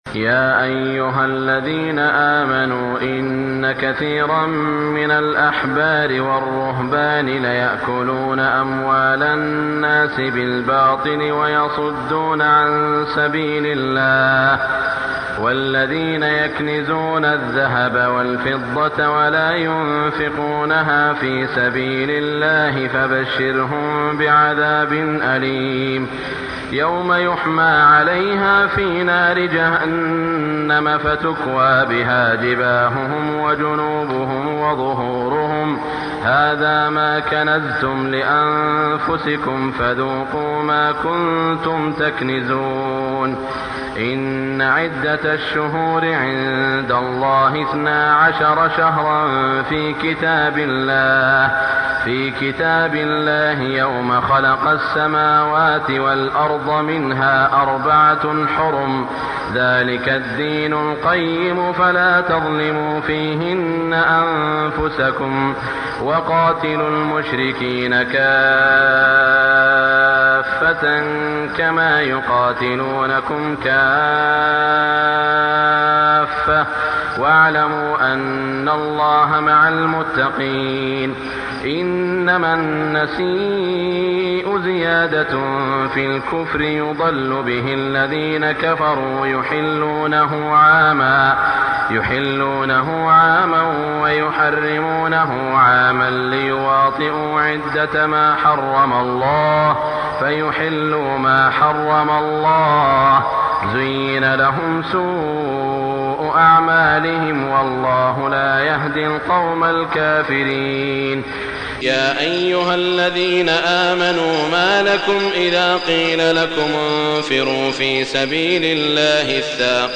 تهجد ليلة 30 رمضان 1418هـ من سورة التوبة (34-129) Tahajjud 30 st night Ramadan 1418H from Surah At-Tawba > تراويح الحرم المكي عام 1418 🕋 > التراويح - تلاوات الحرمين